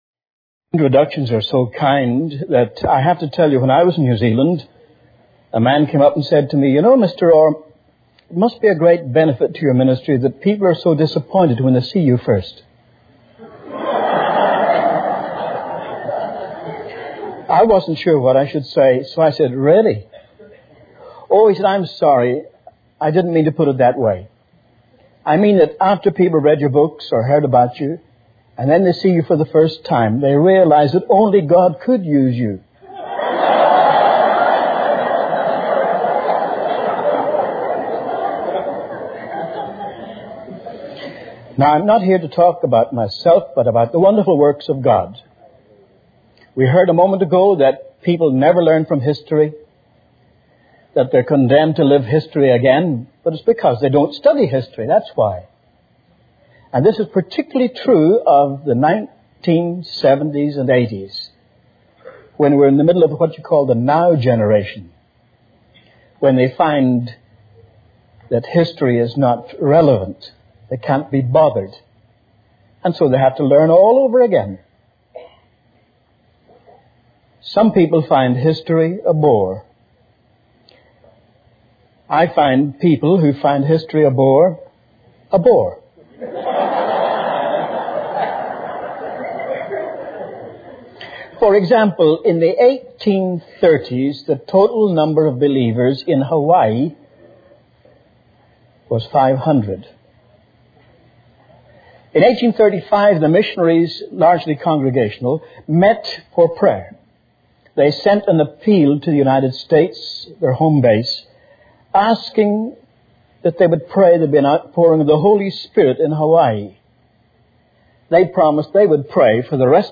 This sermon delves into the historical significance of revivals and awakenings, emphasizing the impact of past movements of God's Spirit in various regions like Hawaii, Scotland, Tonga, and the United States. It highlights the transformative power of prayer, the spread of the gospel, and the challenges faced by believers in different denominations during times of revival.